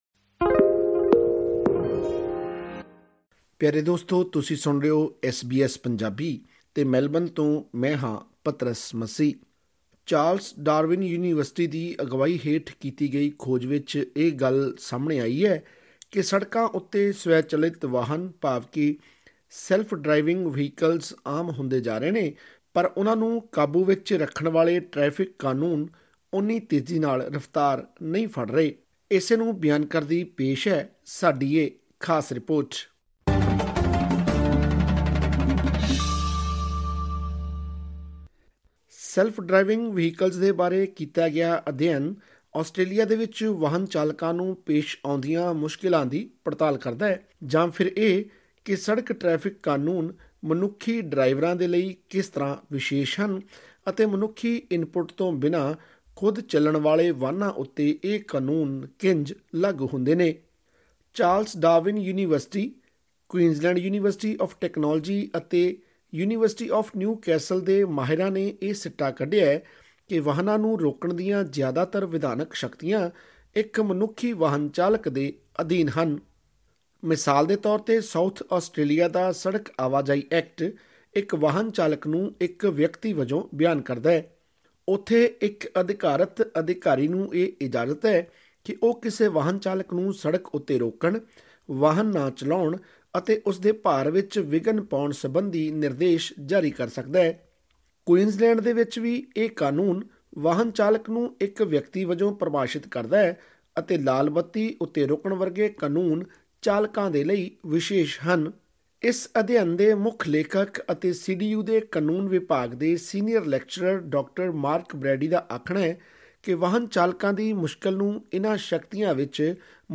ਇੱਕ ਅਧਿਐਨ ਵਿੱਚ ਸਾਹਮਣੇ ਆਇਆ ਹੈ ਕਿ ਆਸਟ੍ਰੇਲੀਆ ਦੀਆਂ ਸੜਕਾਂ ਉੱਤੇ ਸਵੈ-ਚਲਿਤ ਵਾਹਨ ਭਾਵ ਕਿ ‘ਸੈਲਫ ਡ੍ਰਾਈਵਿੰਗ ਵਹੀਕਲਜ਼’ ਆਮ ਹੁੰਦੇ ਜਾ ਰਹੇ ਹਨ ਪਰ ਉਨ੍ਹਾਂ ਨੂੰ ਕਾਬੂ ਵਿੱਚ ਰੱਖਣ ਵਾਲੇ ਟ੍ਰੈਫਿਕ ਕਾਨੂੰਨ ਤੇਜ਼ ਰਫ਼ਤਾਰ ਨਹੀਂ ਫੜ ਪਾ ਰਹੇ। ਤਿੰਨ ਯੂਨੀਵਰਸਿਟੀਆਂ ਦੇ ਮਾਹਿਰਾਂ ਵਲੋਂ ਕੀਤੇ ਅਧਿਐਨ ਦੇ ਨਤੀਜੇ ਮੁਤਾਬਿਕ ਭਵਿੱਖ ਵਿੱਚ ਅਜਿਹੇ ਵਾਹਨਾਂ ਦਾ ਰੁਝਾਨ ਵਧਣ ਦੇ ਨਾਲ ਟੈ੍ਫਿਕ ਕਾਨੂੰਨਾਂ ਦੀ ਸਮੱਸਿਆ ਹੋਰ ਵੱਧ ਸਕਦੀ ਹੈ। ਹੋਰ ਵੇਰਵੇ ਲਈ ਸੁਣੋ ਇਹ ਆਡੀਓ ਰਿਪੋਰਟ ..